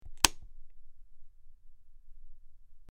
SwitchClicksOnOff PE447607
Switch; Clicks On And Off.